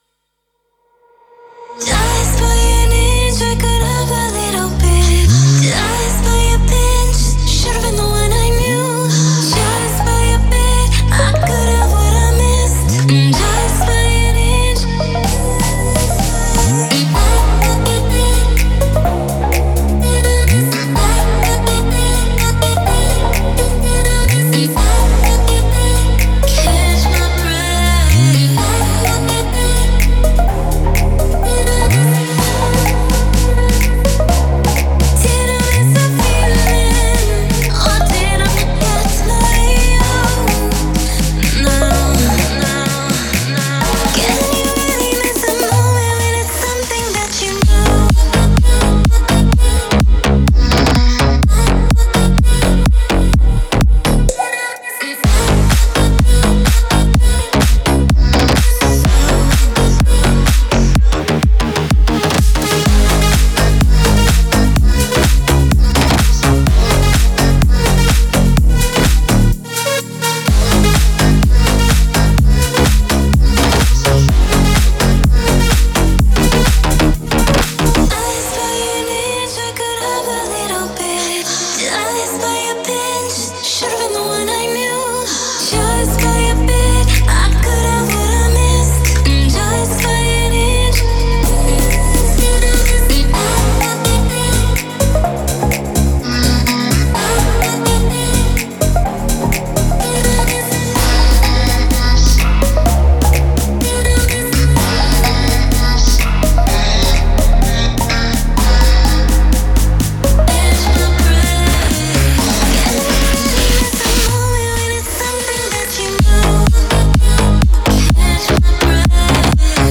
это завораживающая композиция в жанре электронной музыки